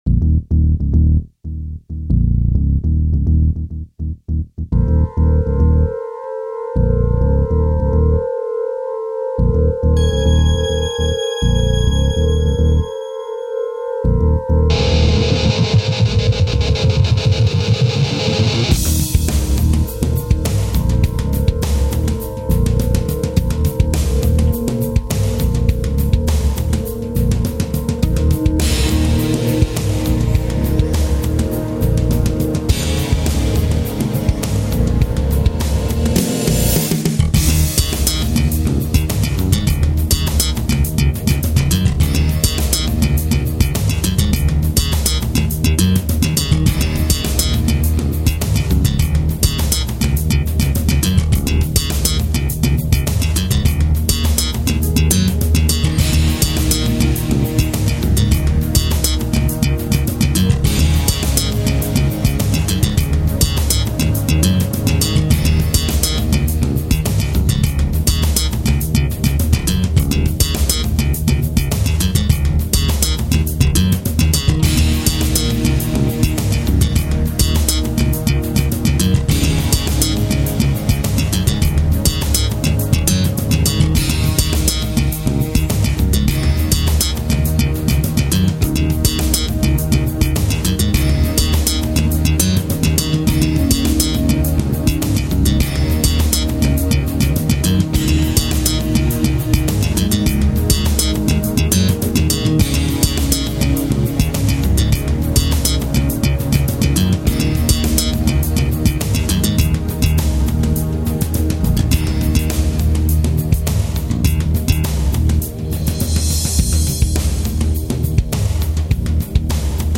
片头音乐